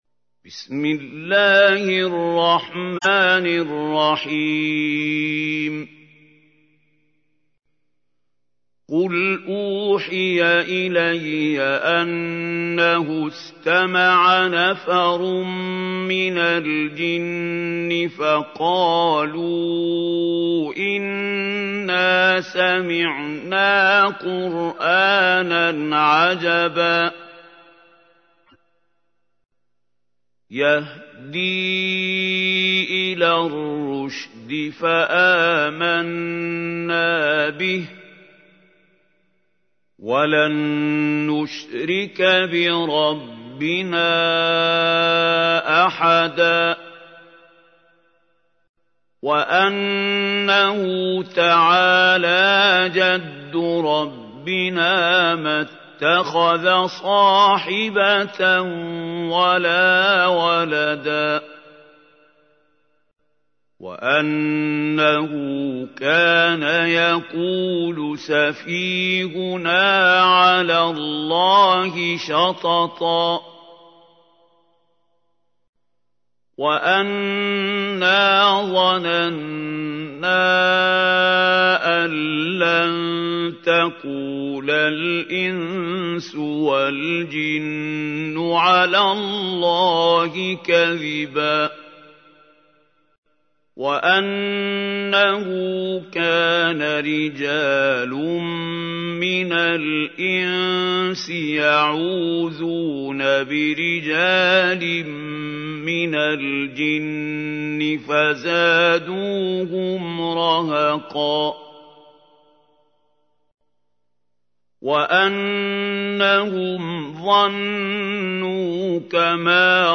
تحميل : 72. سورة الجن / القارئ محمود خليل الحصري / القرآن الكريم / موقع يا حسين